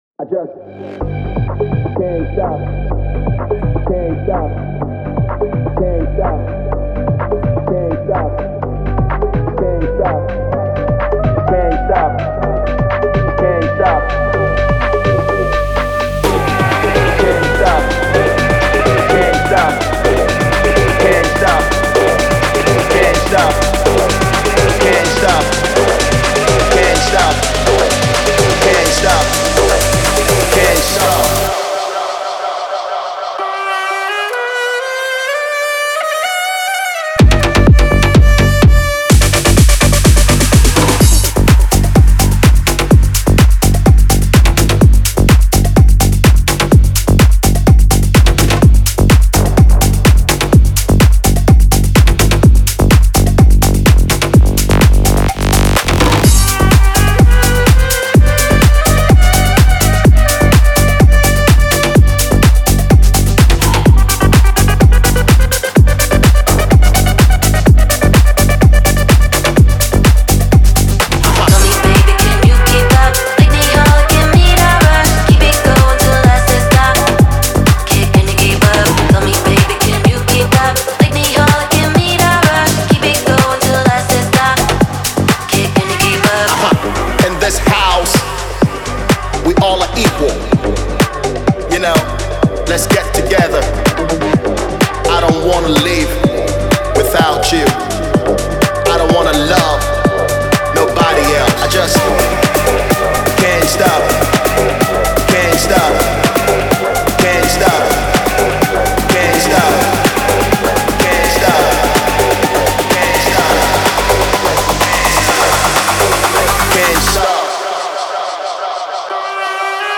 • Жанр: Electronic, House